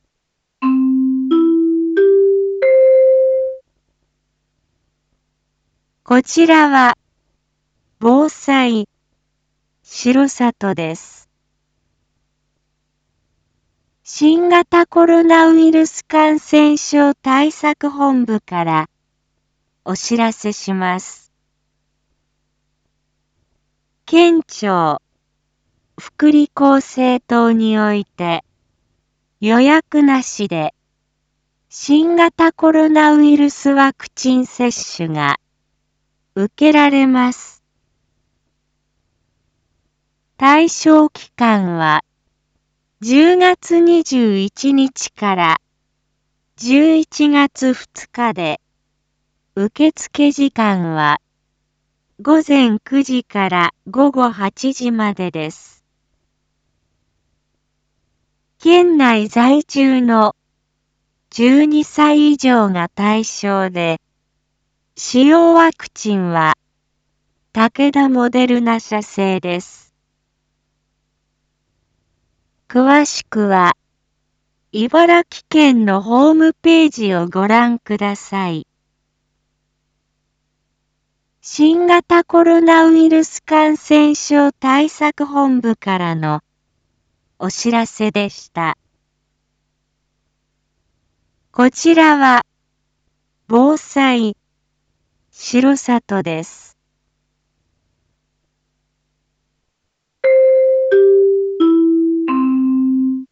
一般放送情報
Back Home 一般放送情報 音声放送 再生 一般放送情報 登録日時：2021-10-22 19:01:45 タイトル：10/22 19時 放送分 インフォメーション：こちらは防災しろさとです。